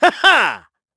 KaselB-Vox_Happy4_kr_b.wav